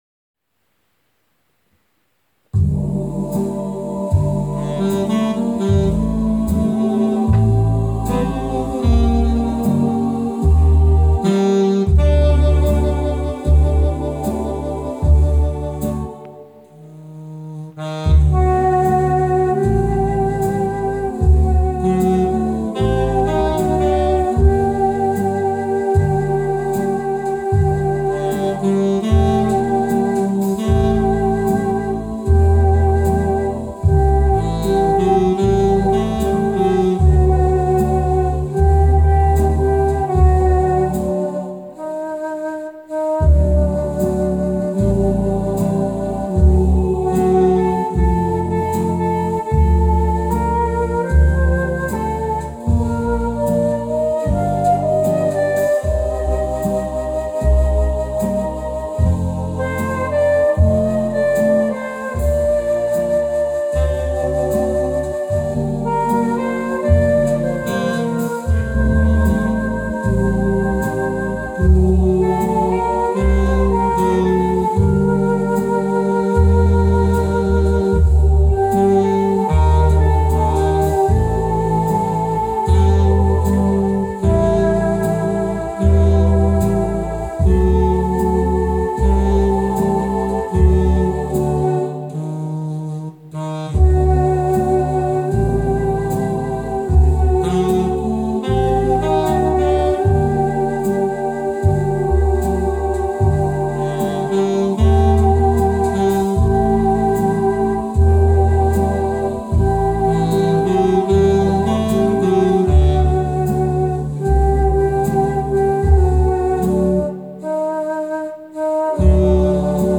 Petite_Fleur_soprano_2eme.mp3